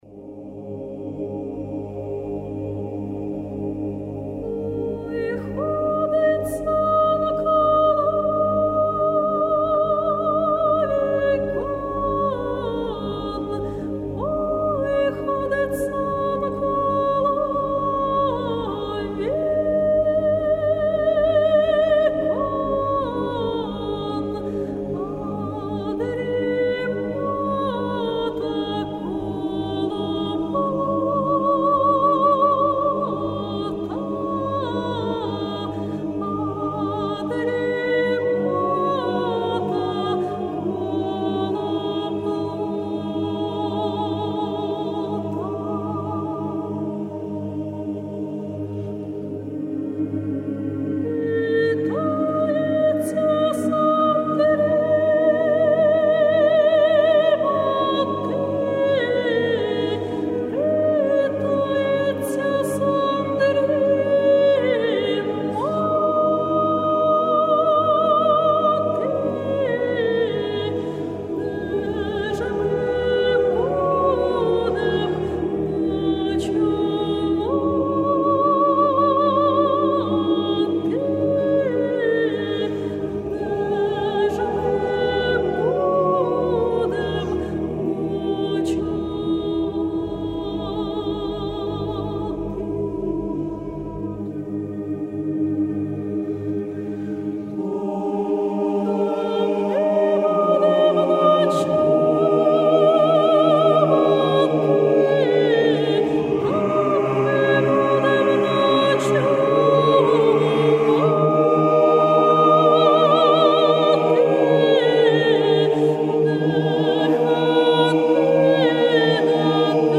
Українська колискова